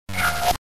meow_click.mp3